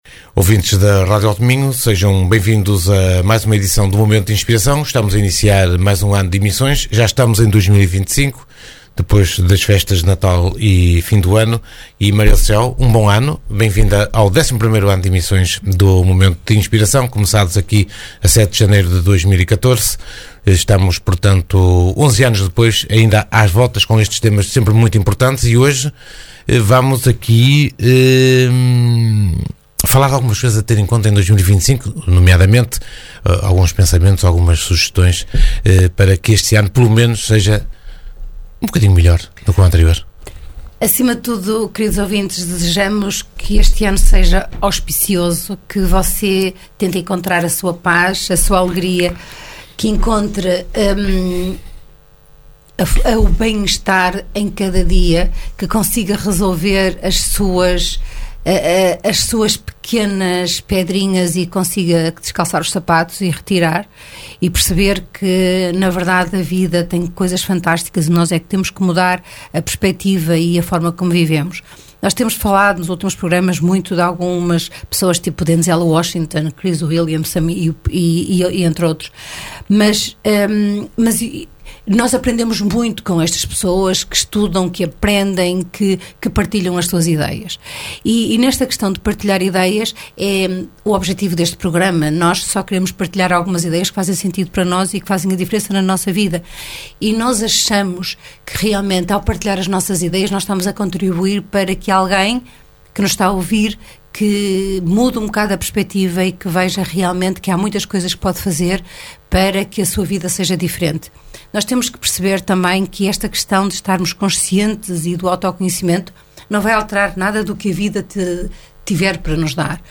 Momento de Inspiração (programa) | Segundas 22h.